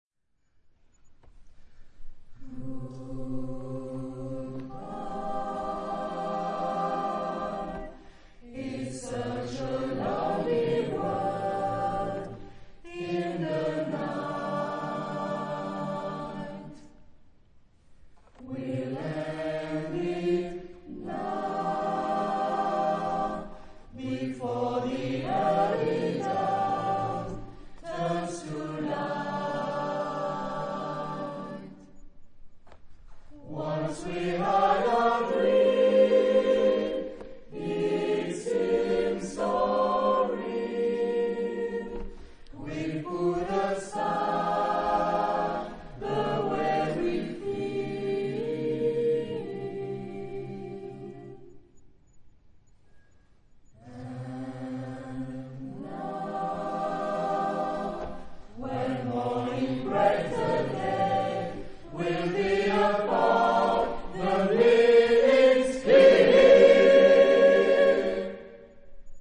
Genre-Style-Form: Contemporary ; Choral jazz ; Close harmony
Type of Choir: SATB  (4 mixed voices )
Tonality: C minor
Discographic ref. : Florilège Vocal de Tours, 2005